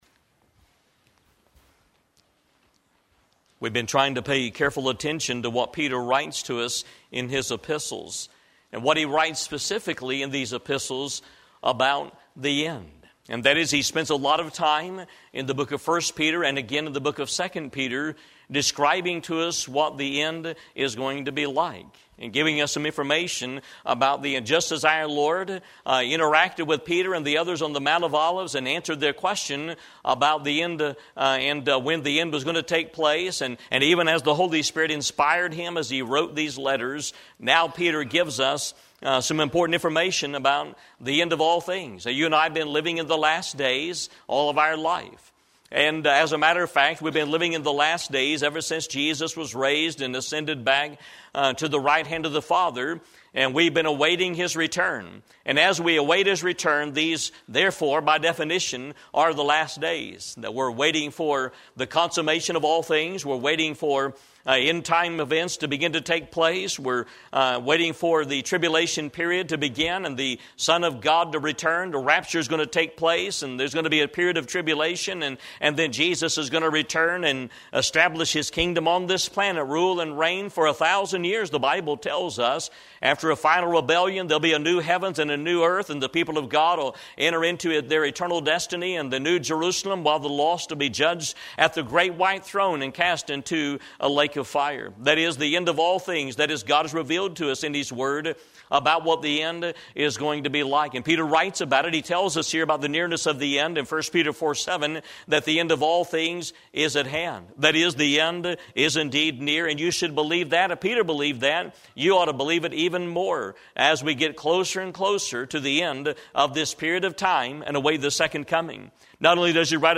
Eastern Gate Baptist Church - An Epistle About the End 12